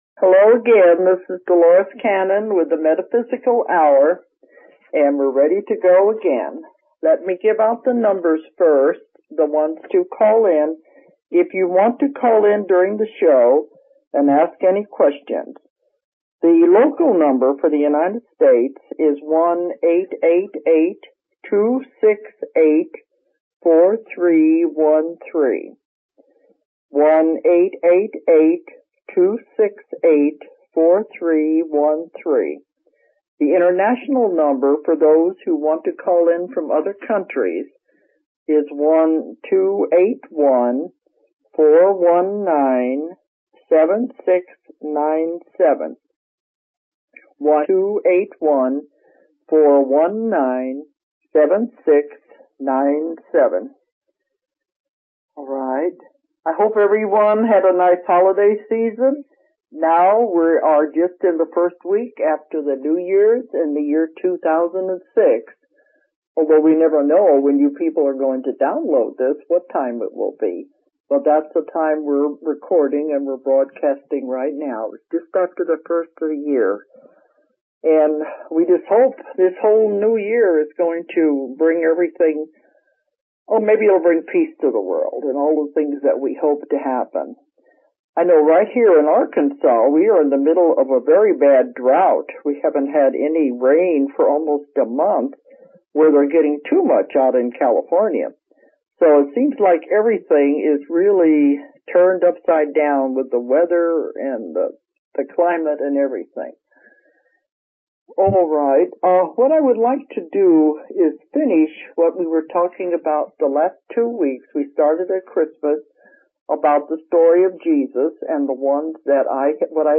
The Metaphysical Hourhosted by Dolores Cannon